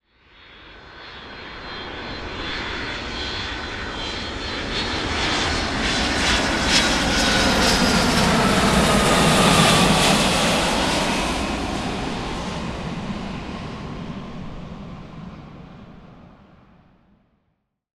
دانلود آهنگ سوت هواپیما 2 از افکت صوتی حمل و نقل
دانلود صدای سوت هواپیما 2 از ساعد نیوز با لینک مستقیم و کیفیت بالا
جلوه های صوتی